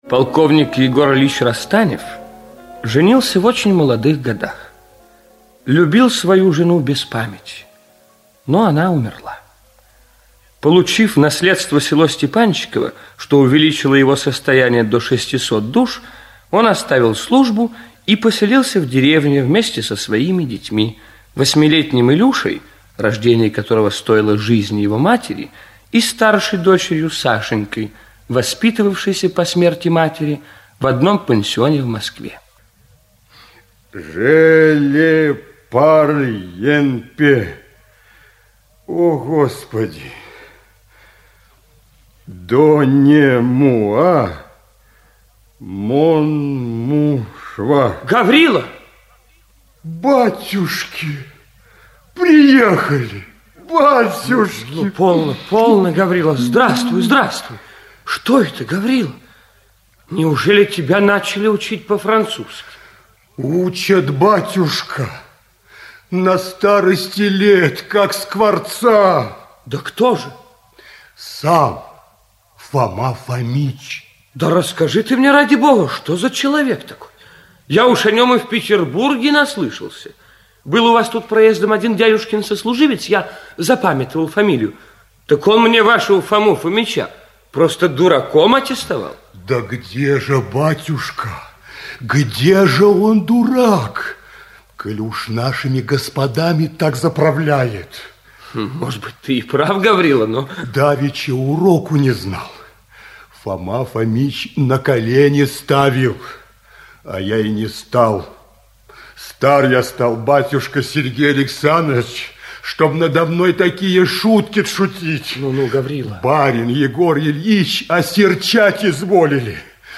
Аудиокнига Село Степанчиково и его обитатели. Аудиоспектакль | Библиотека аудиокниг
Аудиоспектакль Автор Федор Достоевский Читает аудиокнигу Игорь Ильинский.